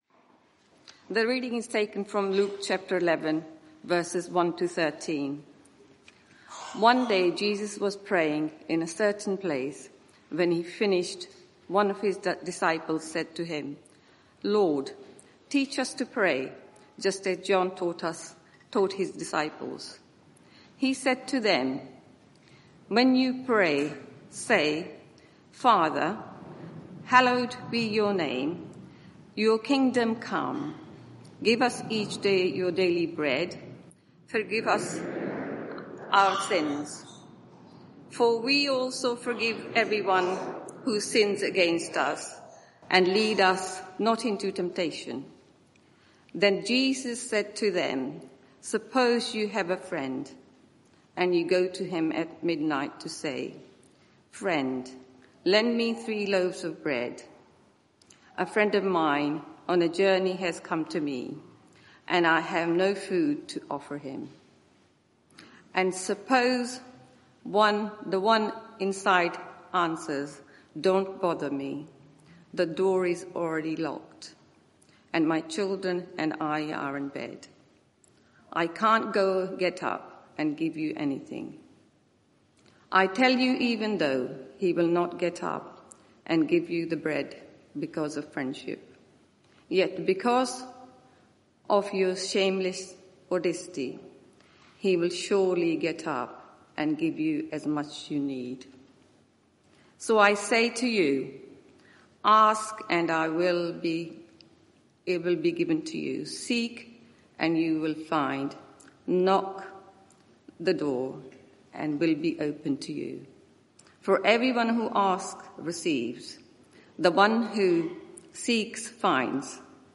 Media for 11am Service on Sun 18th May 2025 11:00 Speaker
Series: Lord Teach us to Pray Theme: Asking God's Help Sermon (audio)